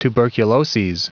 Prononciation du mot tuberculoses en anglais (fichier audio)
tuberculoses.wav